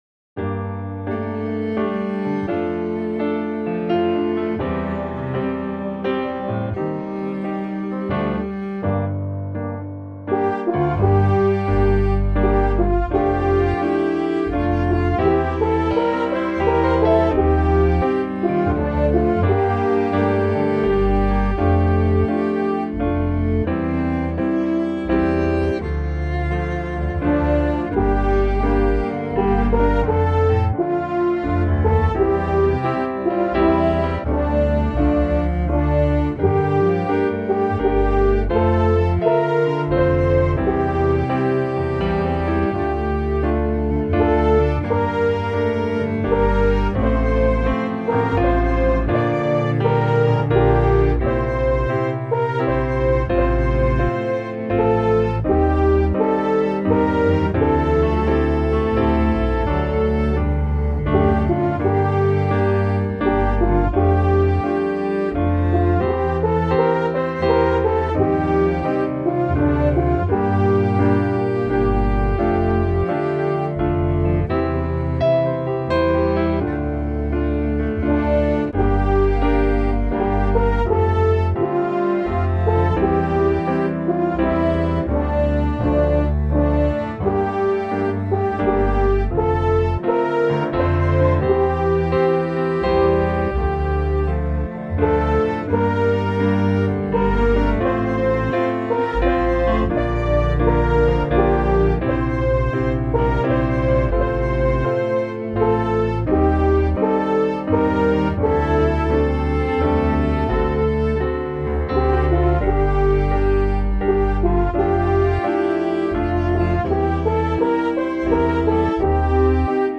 I didn’t bother with the descant in my backing: